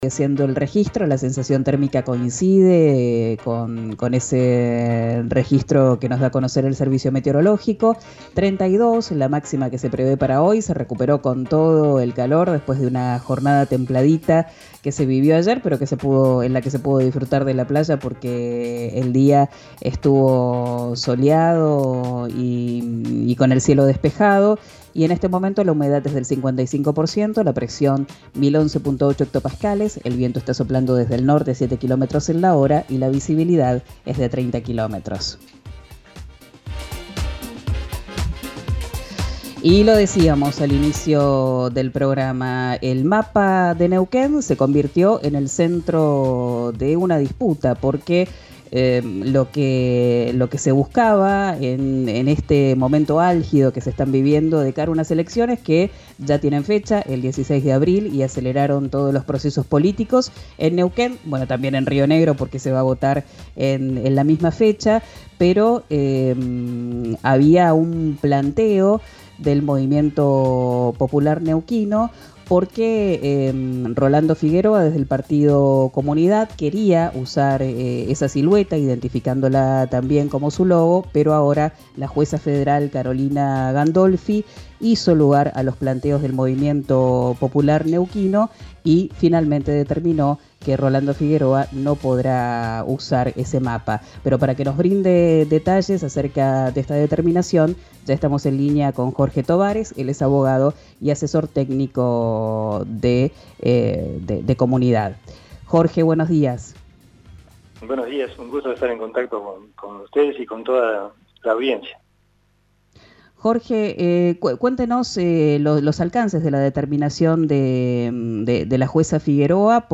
Escuchá la entrevista en 'Quien dijo verano' por RÍO NEGRO RADIO.